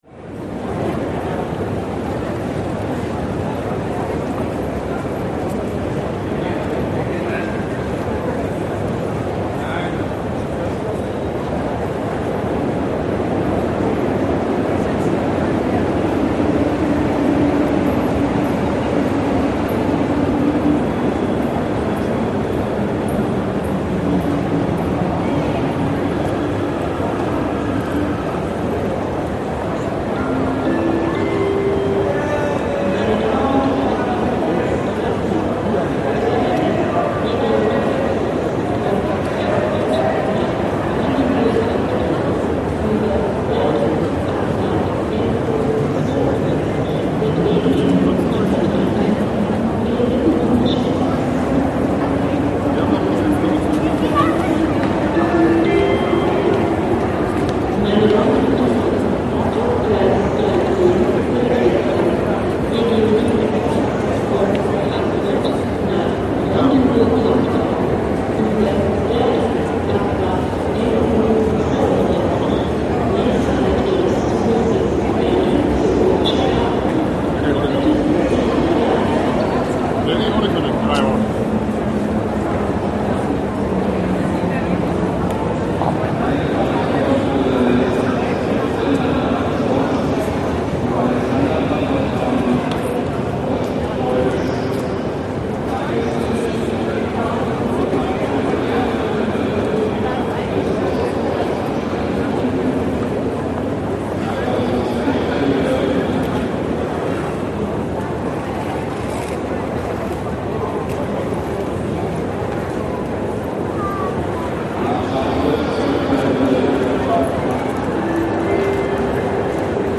Train Station - Bahn Hof - Big PA Hum of Voices
Tags: U Bahn S Bahn Berlin Berlin subway Subway sounds